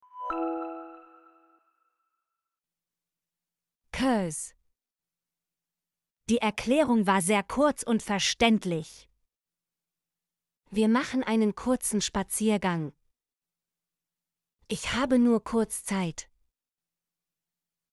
kurz - Example Sentences & Pronunciation, German Frequency List